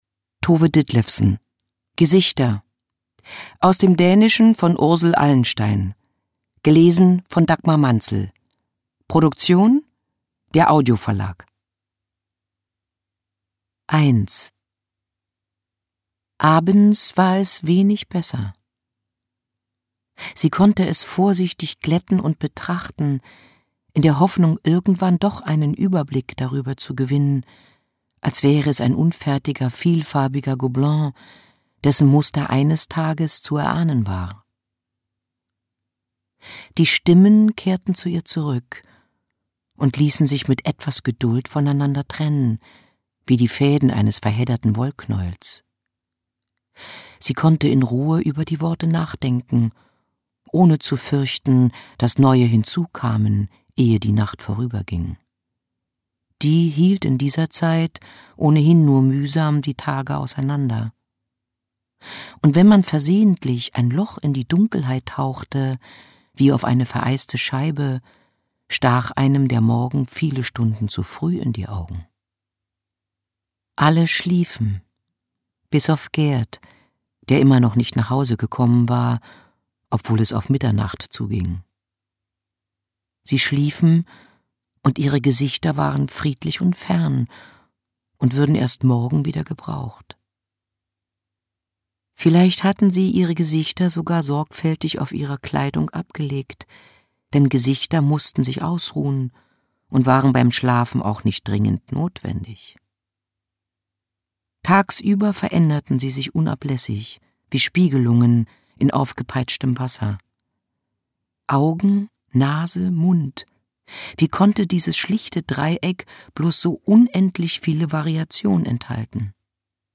Dagmar Manzel (Sprecher)
2022 | Ungekürzte Lesung